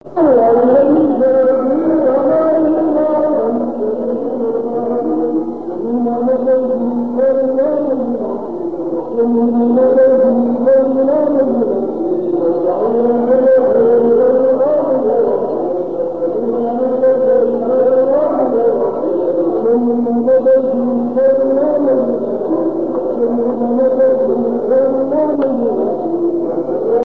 danse : laridé, ridée
Pièce musicale inédite